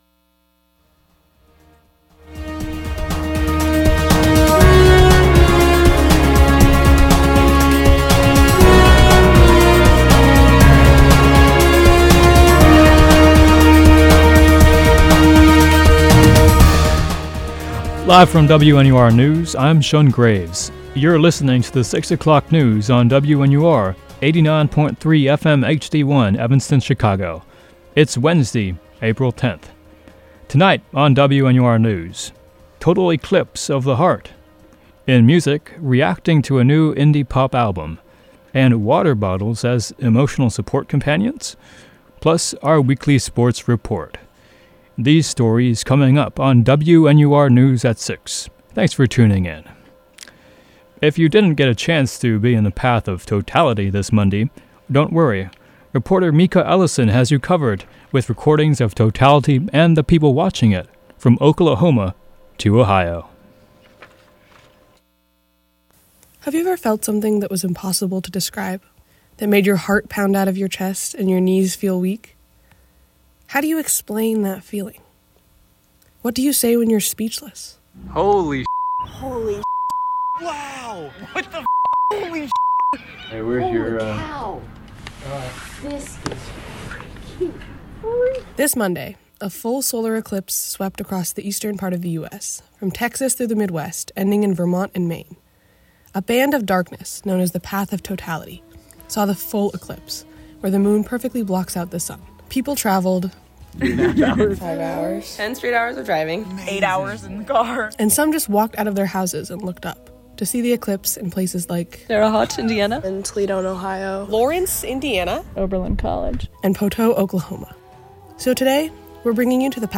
April 10, 2024 Solar eclipse of the heart, Vampire Weekend review, water bottles as emotional support companions, NU Sports Report. WNUR News broadcasts live at 6 pm CST on Mondays, Wednesdays, and Fridays on WNUR 89.3 FM.